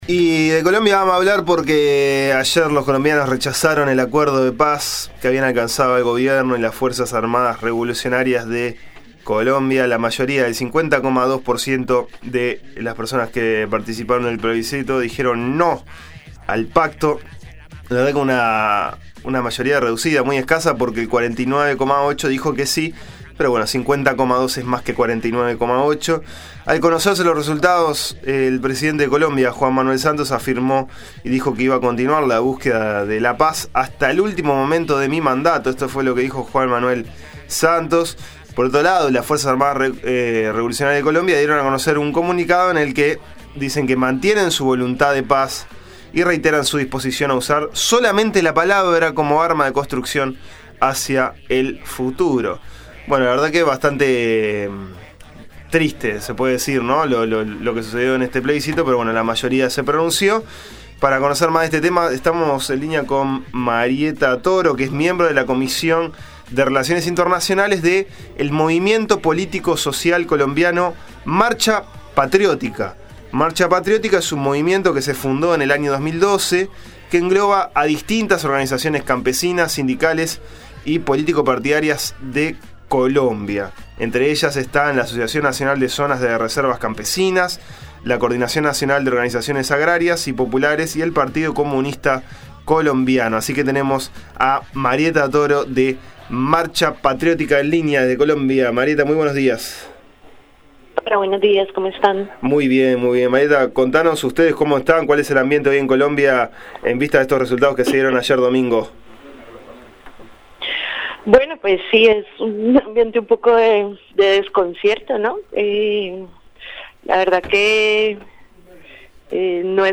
Análisis: colombianos rechazaron acuerdo de paz con las FARC